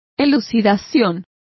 Complete with pronunciation of the translation of elucidation.